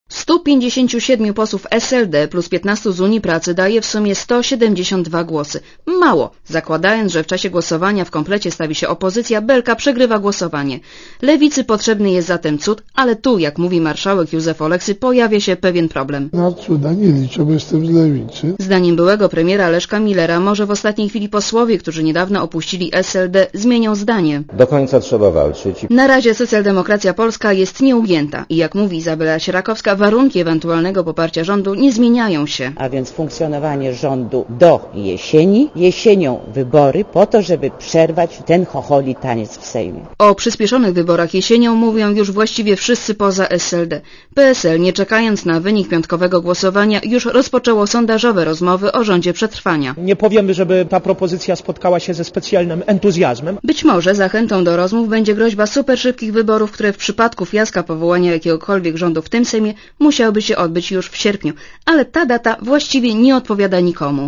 Mało prawdopodobne, by rząd Marka Belki przetrwał piątkowe głosowanie w Sejmie. Poza SLD i Unią Pracy żaden klub nie chce głosować za udzieleniem Belce wotum zaufania. Źródło: RadioZet Relacja reportera Radia ZET Oceń jakość naszego artykułu: Twoja opinia pozwala nam tworzyć lepsze treści.